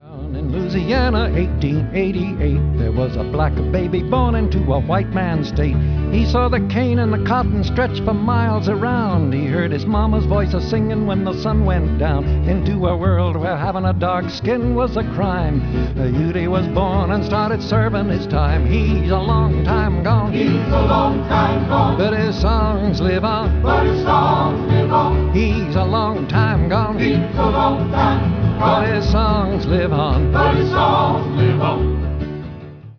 voice, 12-string guitar
bass